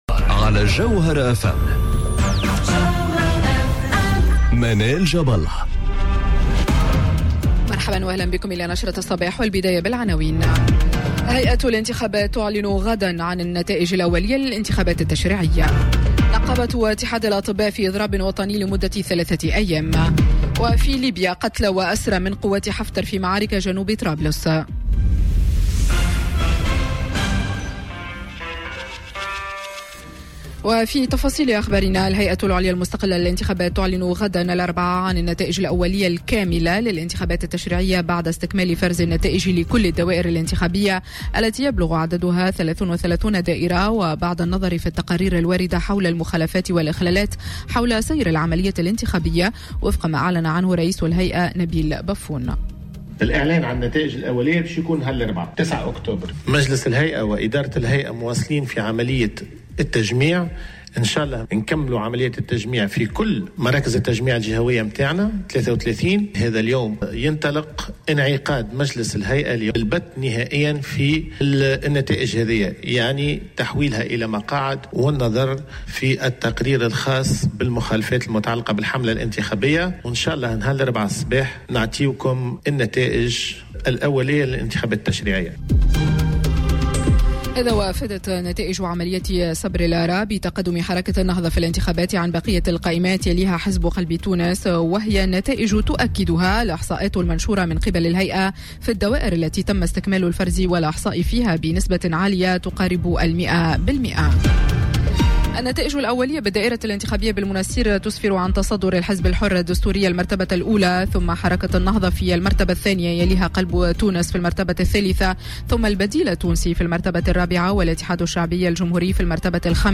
نشرة أخبار السابعة صباحا ليوم الثلاثاء 08 أكتوبر 2019